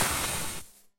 Match Strike
A single match being struck with scratch, flare, and small flame settling
match-strike.mp3